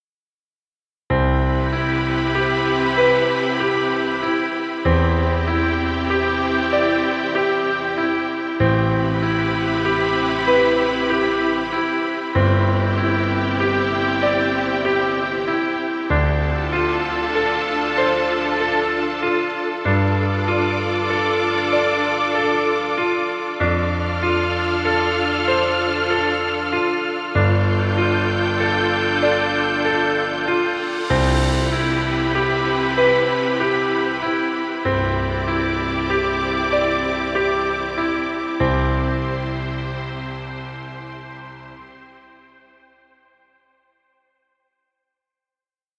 Romantic music. Background music Royalty Free.